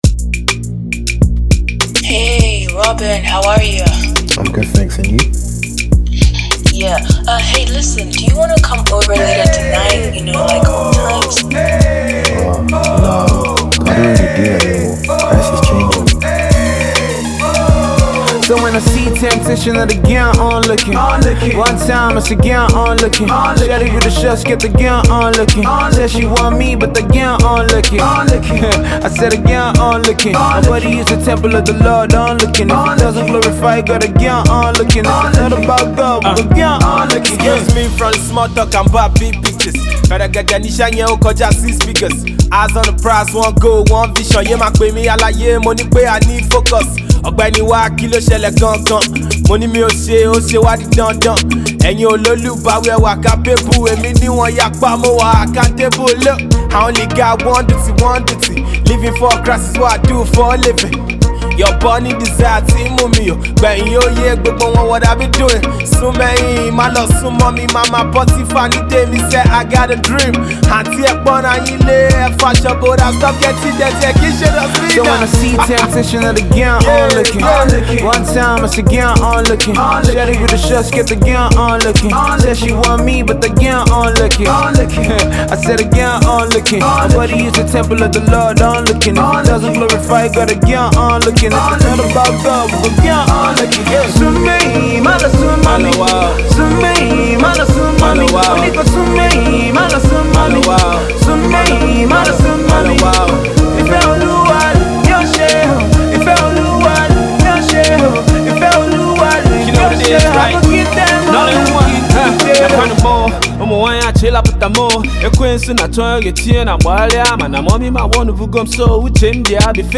Yoruba indigenous artist
Igbo indigenous rapper